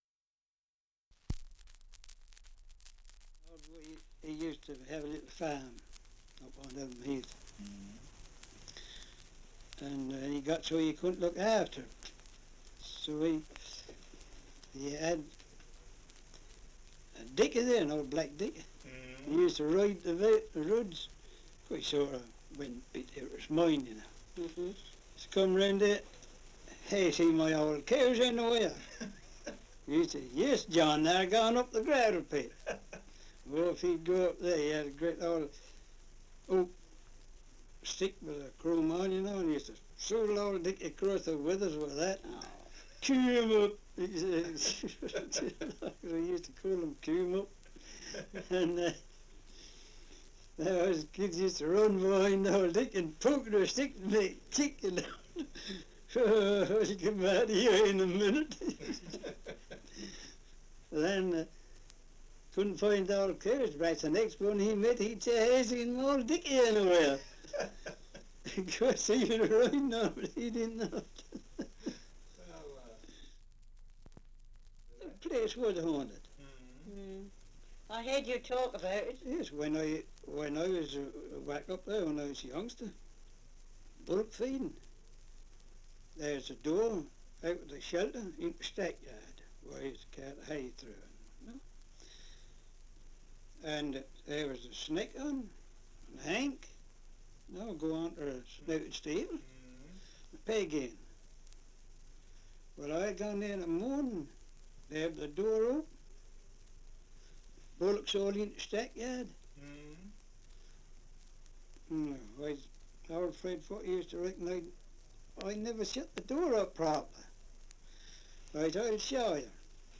Survey of English Dialects recording in North Elmham, Norfolk
78 r.p.m., cellulose nitrate on aluminium